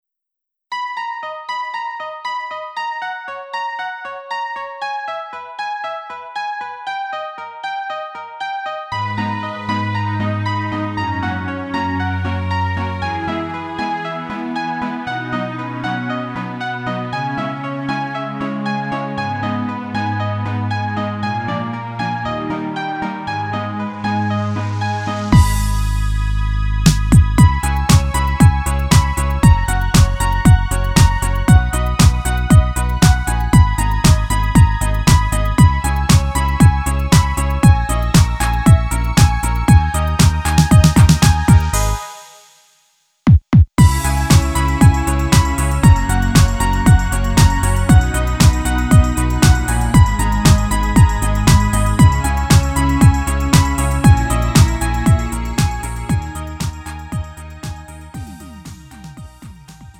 음정 원키 3:41
장르 가요 구분 Lite MR